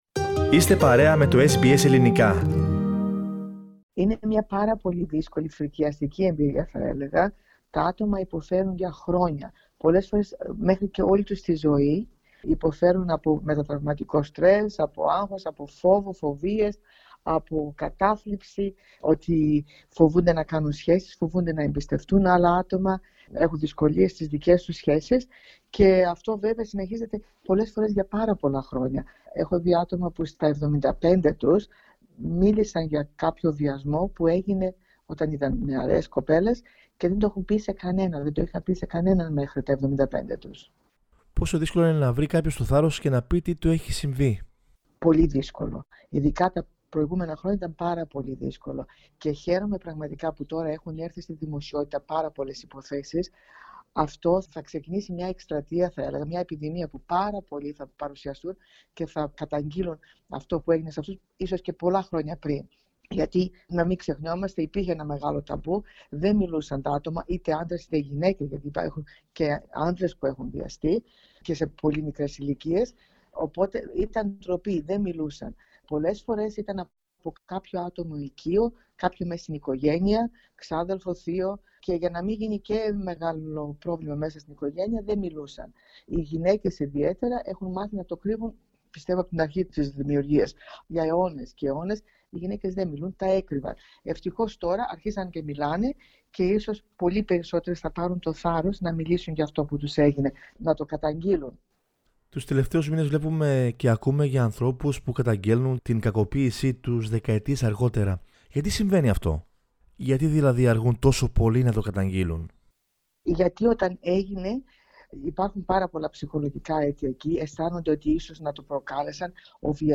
μιλώντας στο Ελληνικό Πρόγραμμα της Ραδιοφωνίας SBS προσπαθεί να δώσει απαντήσεις σε κρίσιμα ερωτήματα